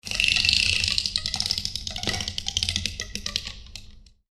テープのり短い.mp3